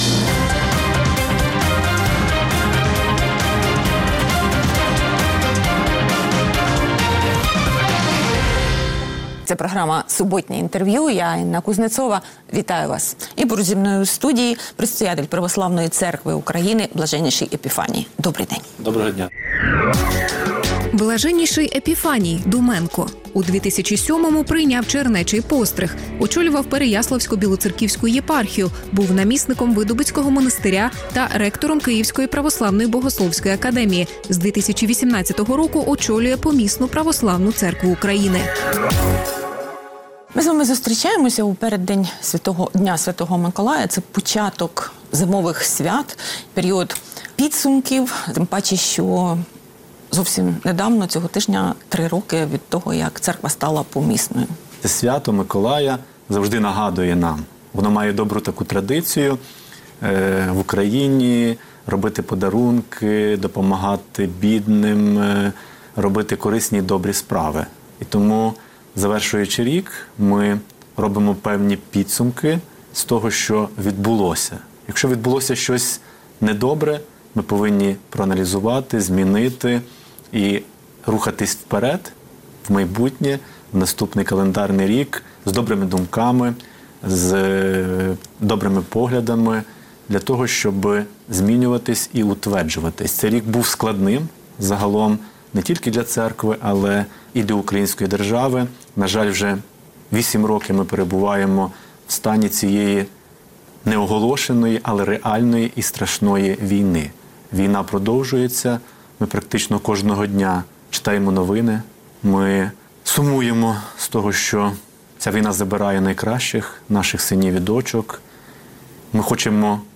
Суботнє інтерв’ю | Епіфаній, предстоятель ПЦУ